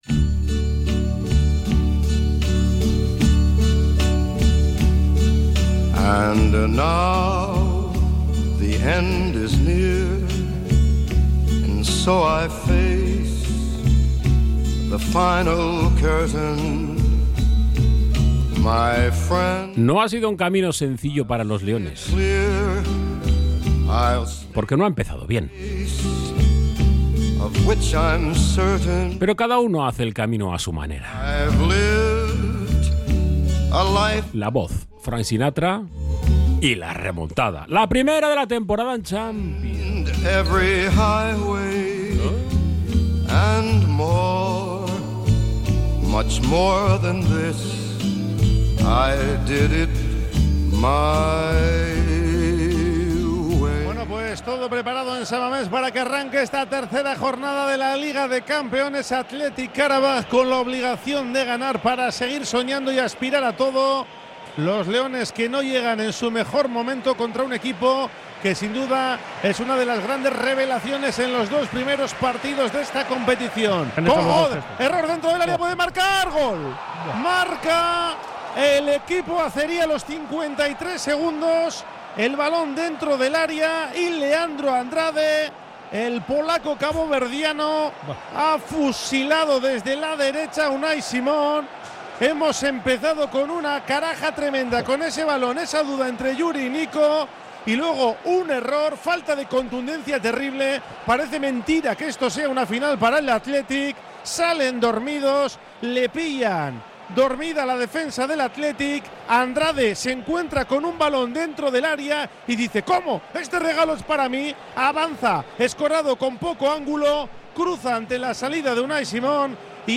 Montaje musical con los bacalaos de la victoria del Athletic en Champions